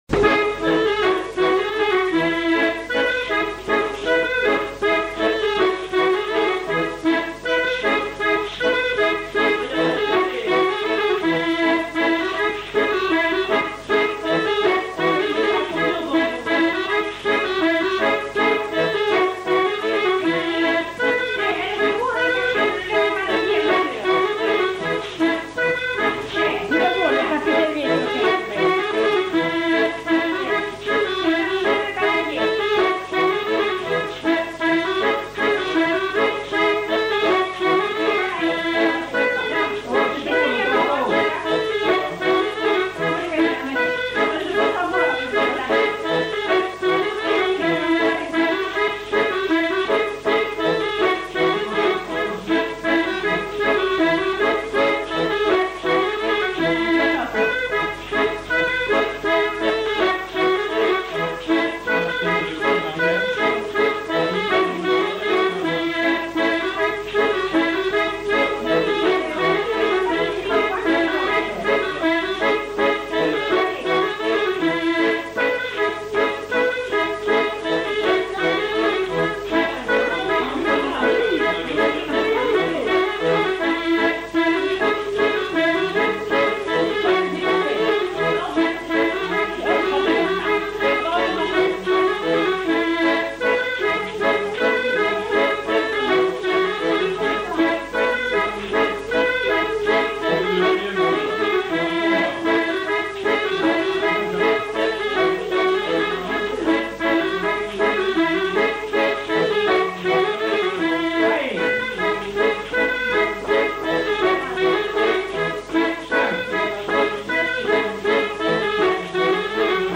Répertoire de danses joué à l'accordéon diatonique
enquêtes sonores
Polka de Pissos